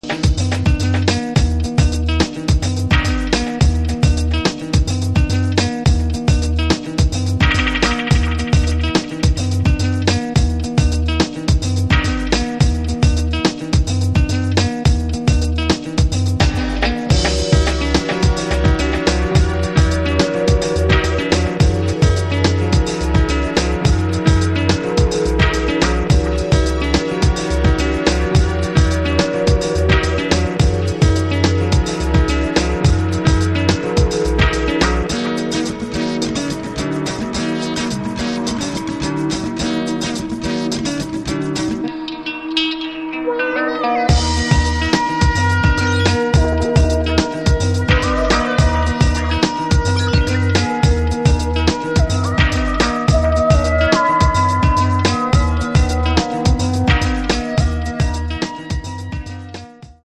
Eastern Dubtempo
Awesome summer vibes…